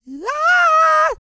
E-CROON 3026.wav